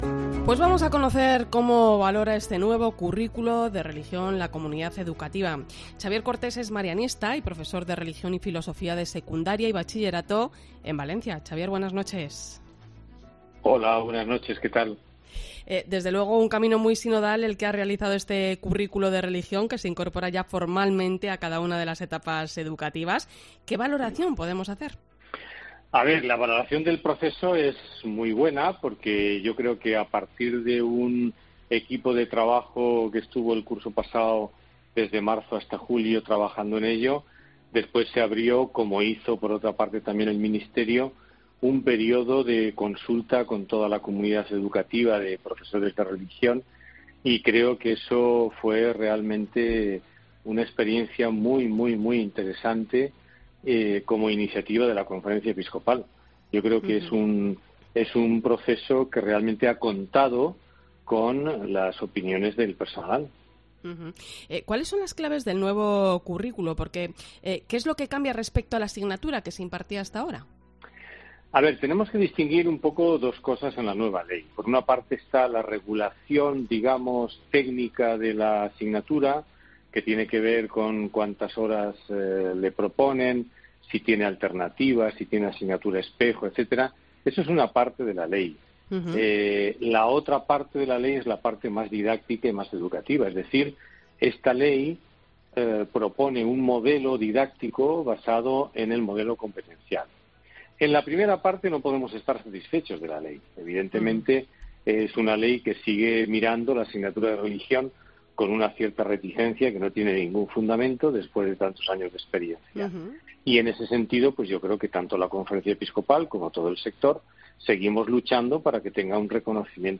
Conocemos cómo valora el nuevo currículo de religión la comunidad educativa con la presencia de un profesor de la asignatura en 'La Linterna de la Iglesia'